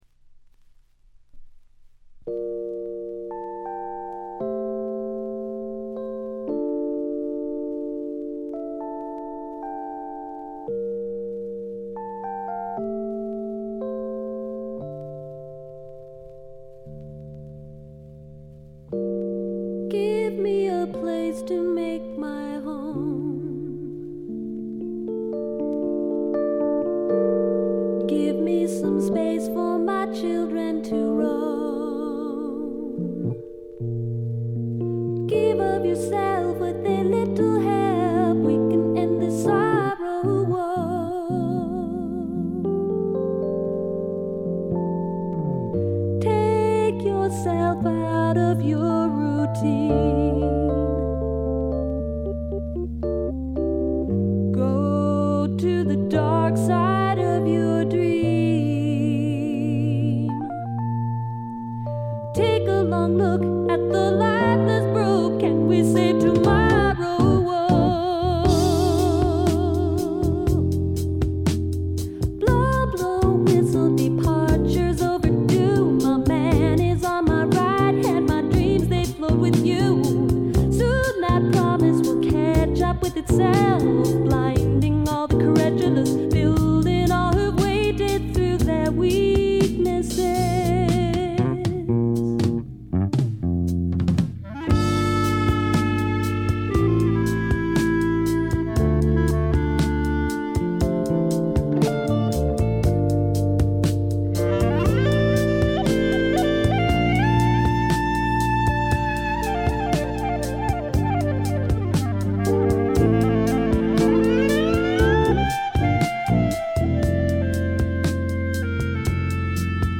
わずかなノイズ感のみ。
気を取り直して・・・これはシアトル産の自主制作盤で、知られざるAOR系女性シンガーソングライターの快作です。
試聴曲は現品からの取り込み音源です。
Acoustic Guitar, Harpsichord, Mandolin, Piano
Recorded At - Sea-West Studios, Seattle